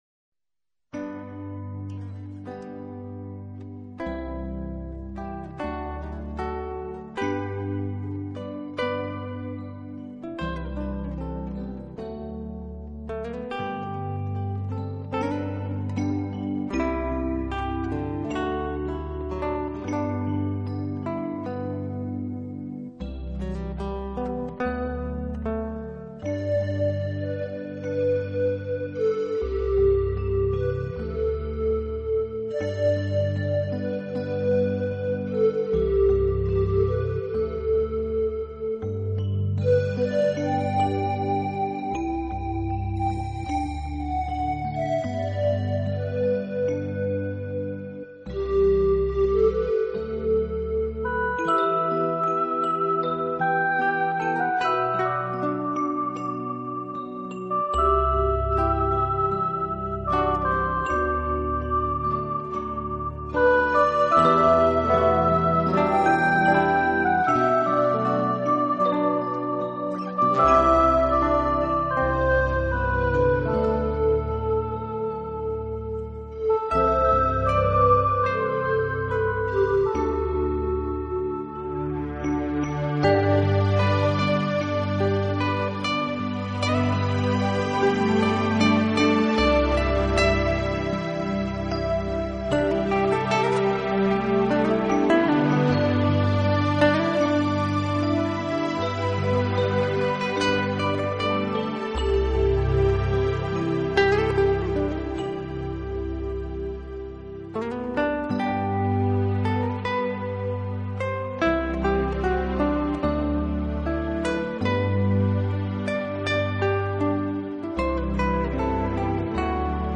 新世纪纯音乐
专辑语言：纯音乐
整张专辑渗透着古老土地的节奏和奥秘，三白金的销量足见他的冥想魅力。